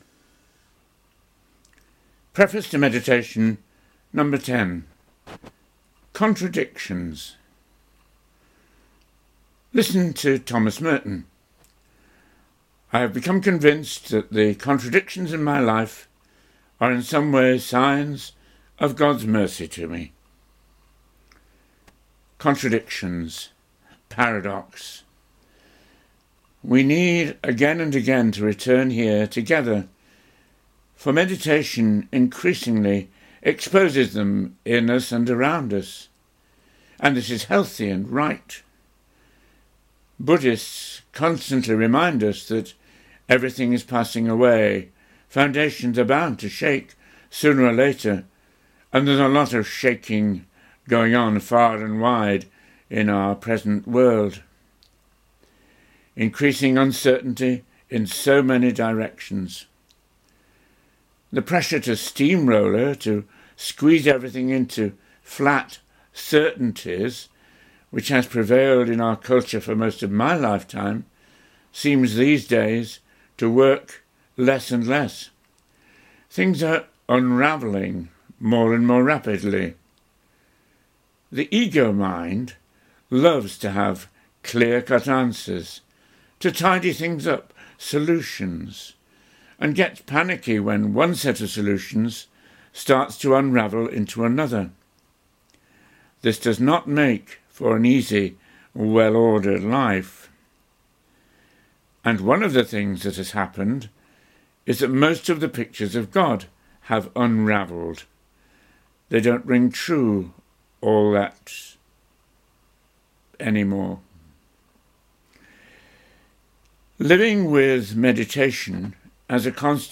Recorded Talks